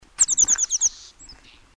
Amerikankirvinen / Buff-bellied Pipit (Anthus rubescens)
Lentoon lähtevän linnun ääni / Flight call